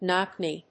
アクセントknóck knèe